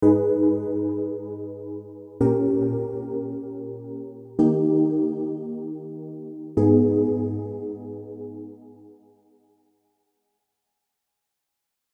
R&B Archives
Serum_Keys_Aurora_AV